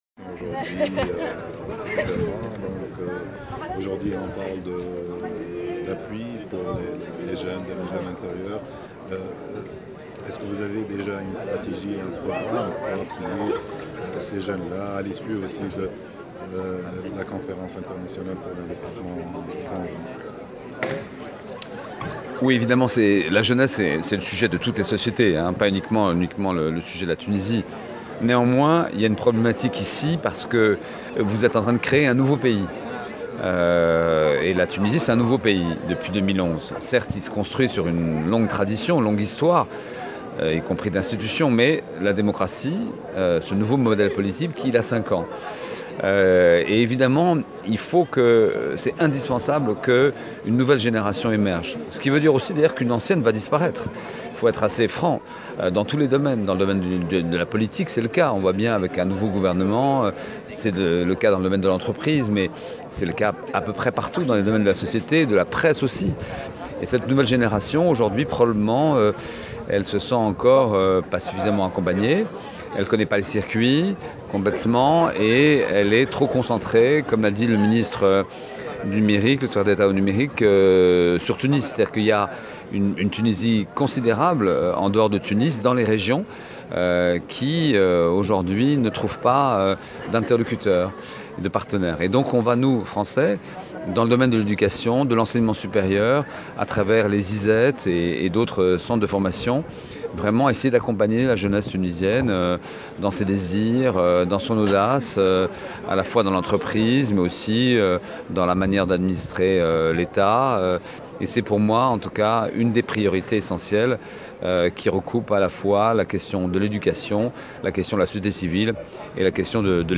وقال في تصريحات صحفية على هامش ندوة نظمتها اليوم وزارة تكنولوجيا الاتصال والاقتصاد الرقمي إن الاشكالية تكمن في أن تونس بصدد إحداث "دولة" جديدة بنظام جديد بعد دخولها مرحلة الانتقال الديمقراطي مما ادى إلى ظهور جيل جديد من الشباب في جميع المجالات السياسية والاقتصادية والاجتماعية وهو بحاجة إلى مساندة تسعى فرنسا لتوفيرها، بحسب تعبيره.